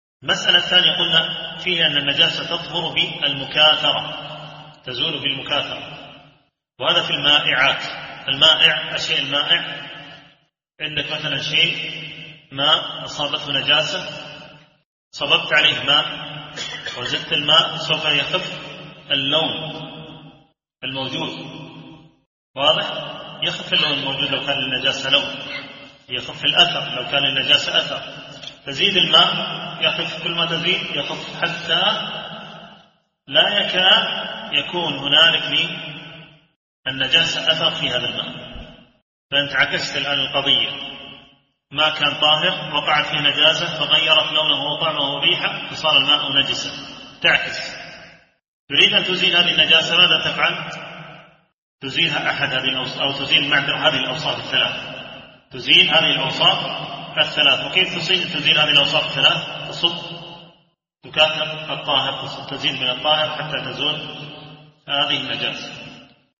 التنسيق: MP3 Mono 44kHz 32Kbps (CBR)